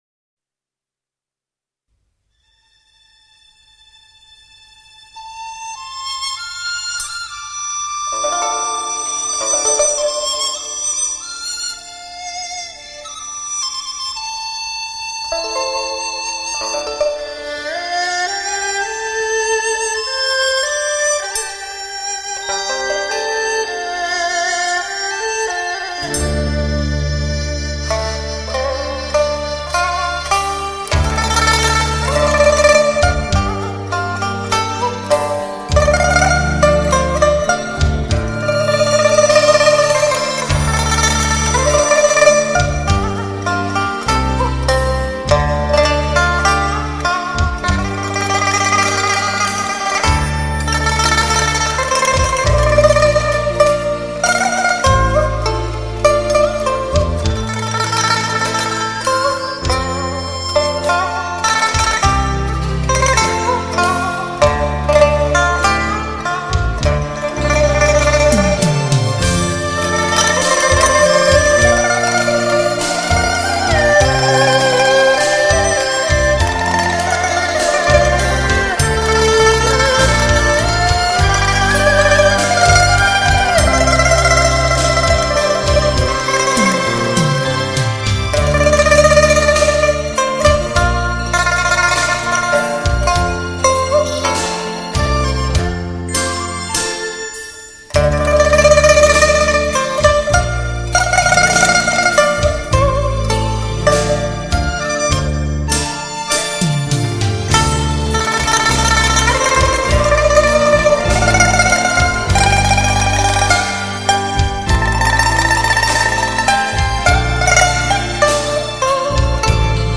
琵琶独奏
他的演奏激情与韵味并重，擅长体现不同流派的
为低音质MP3